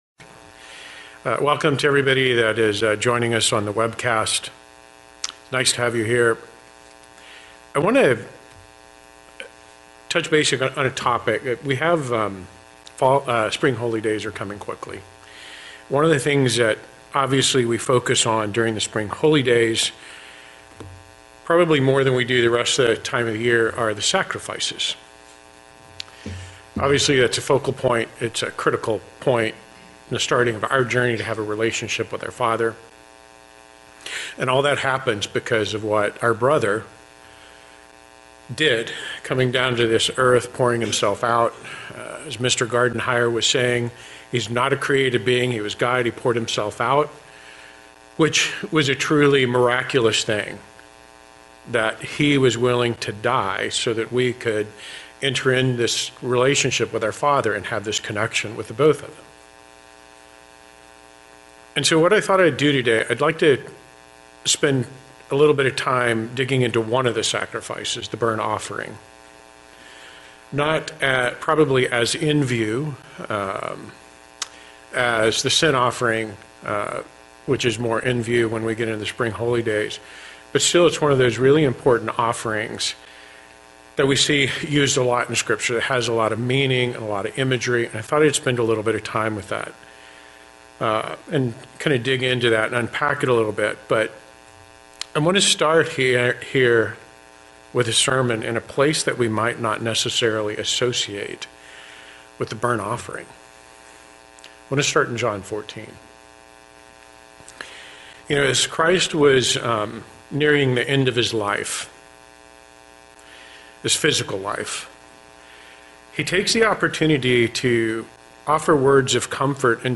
Sermons
Given in San Diego, CA Redlands, CA Las Vegas, NV